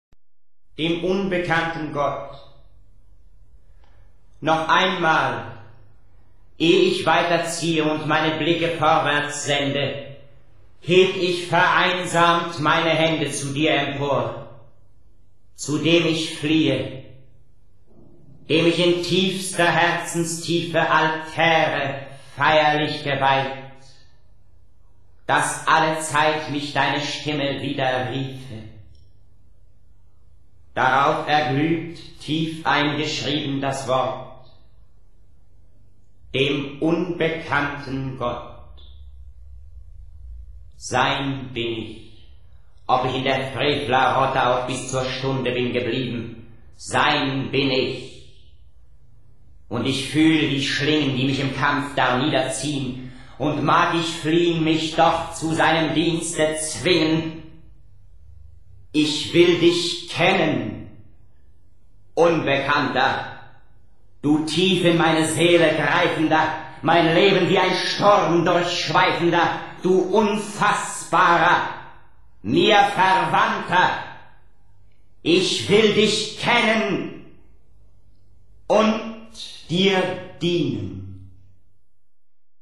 Bei der Deutschen Grammophon hat Klaus Kinski diverse Gedichte Nietzsches rezitiert -